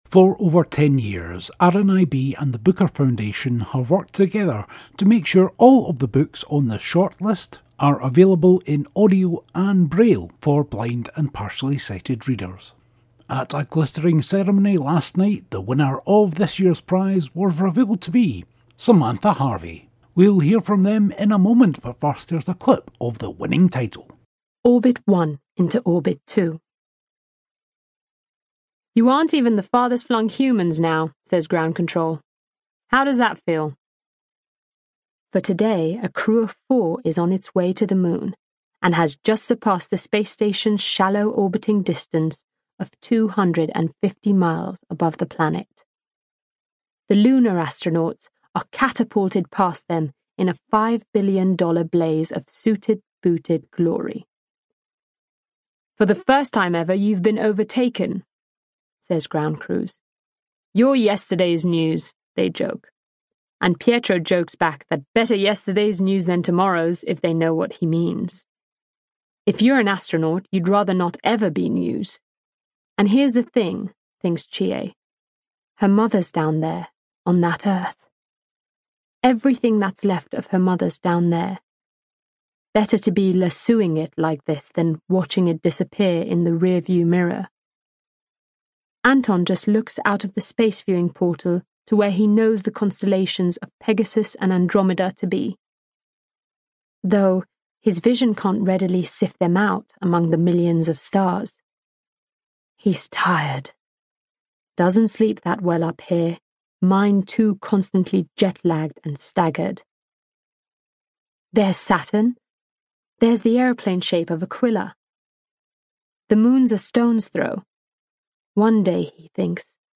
Full interview on Friday's show, but here's a clip from my interview with Booker winner 2024, Samantha Harvey